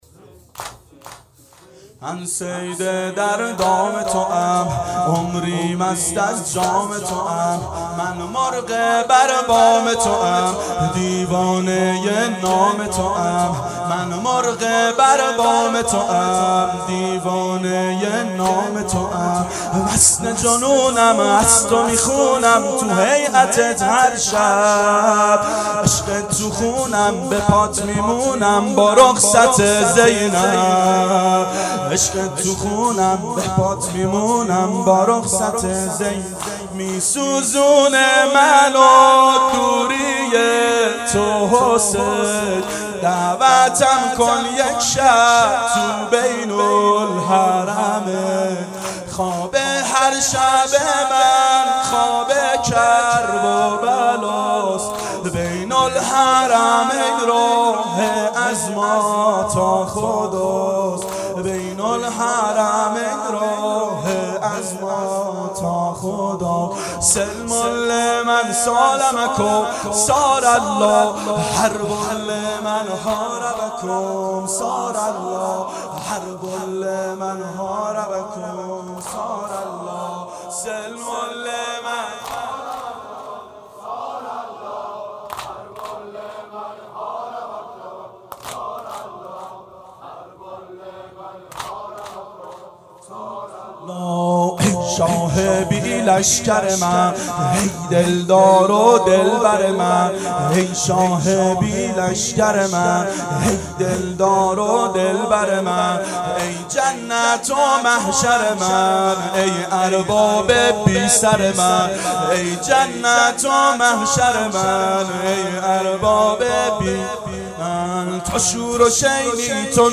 دهه اول صفر سال 1390 هیئت شیفتگان حضرت رقیه س شب اول